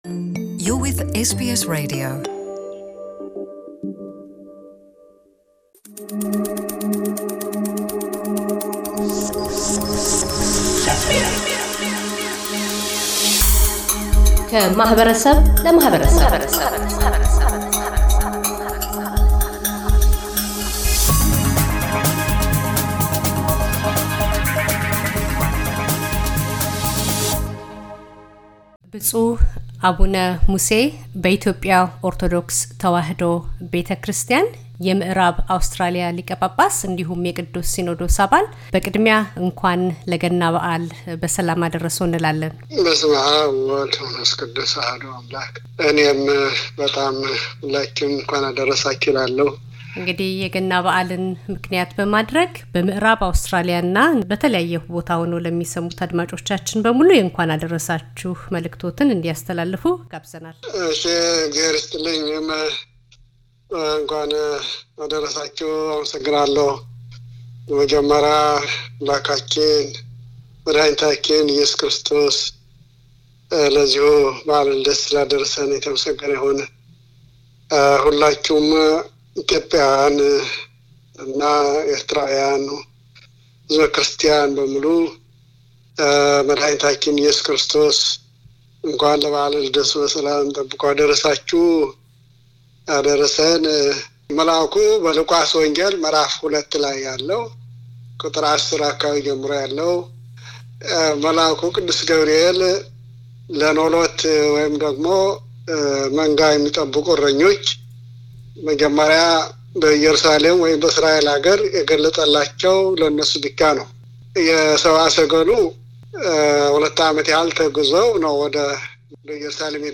ብፁዕ አቡነ ሙሴ በኢትዮጵያ ኦርቶዶክስ ተዋህዶ ቤተክርስቲያን የም ዕራብ አውስትራሊያ ሊቀ ጳጳስና የቅዱስ ሲኖዶስ አባል፤ የገና በዓል መልዕክታቸውን ያስተላልፋሉ።